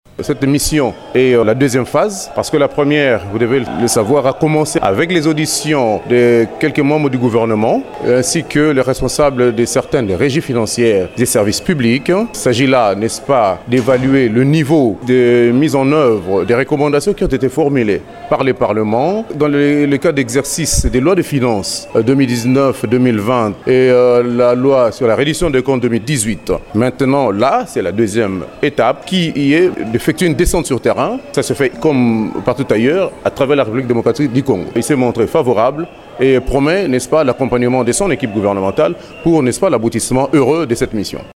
Il s’exprimait ainsi le dimanche 7 novembre devant la presse.